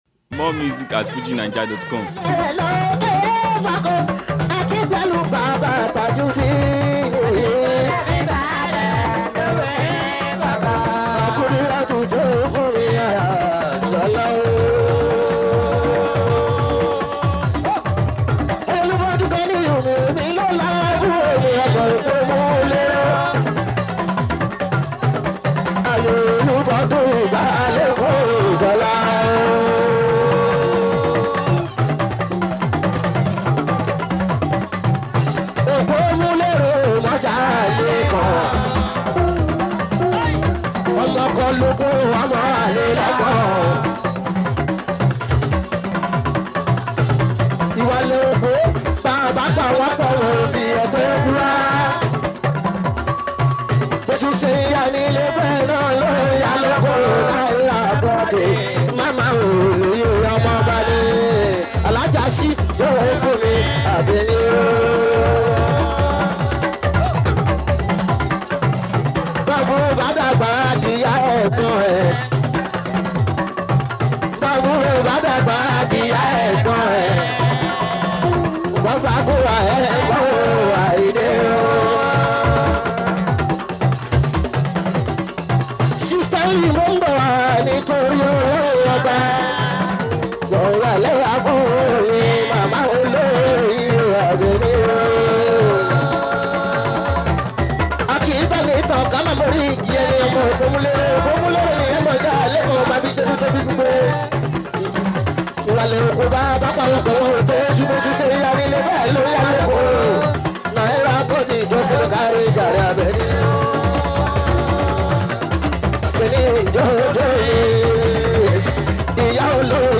Yoruba Fuji